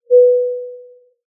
Звук пристегнуть ремни в самолете